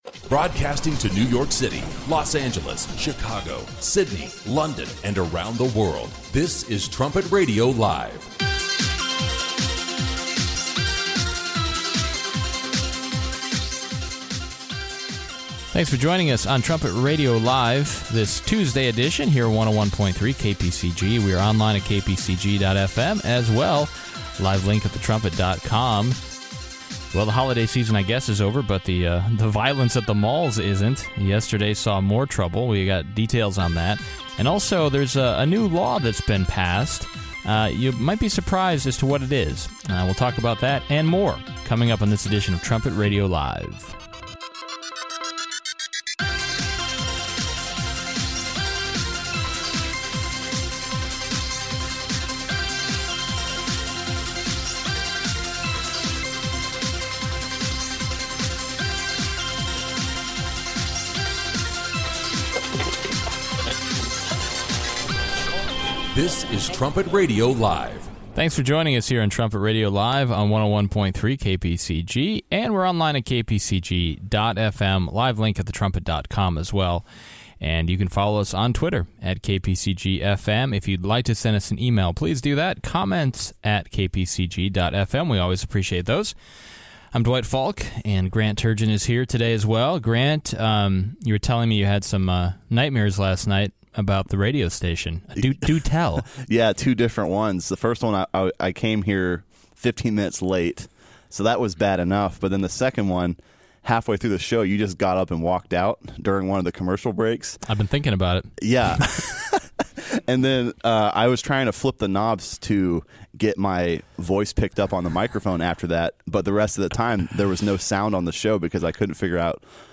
Trumpet Radio Live seeks to provide God’s view of the current headlines in an informative, stimulating, conversational and occasionally humorous way.
trumpet-radio-live-146-trl-today-is-the-free-press-almost-gone.mp3